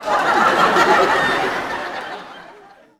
Increased volume of laugh tracks again
Audience Laughing-03.wav